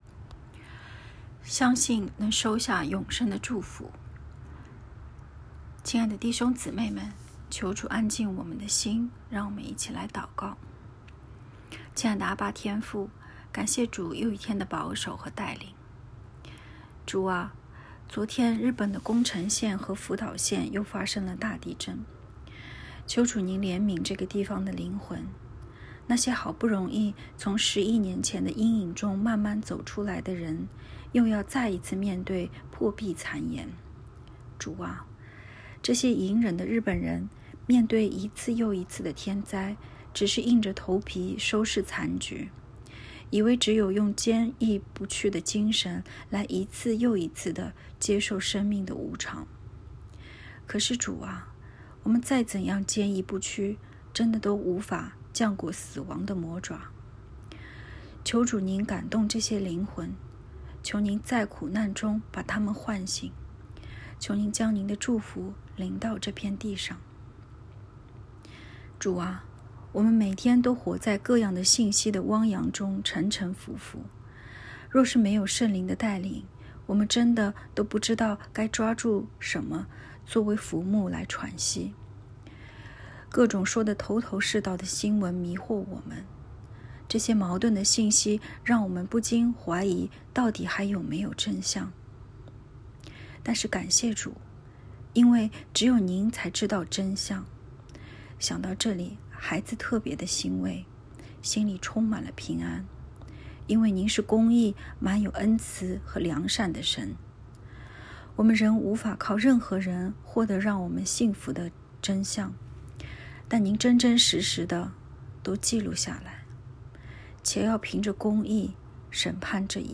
✨晚祷时间✨3月17日（周四）